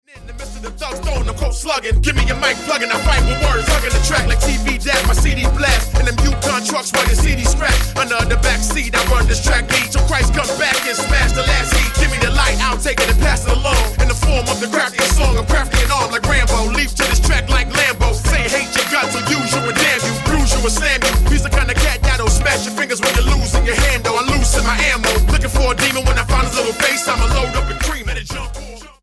Hip-Hop
Style: Gospel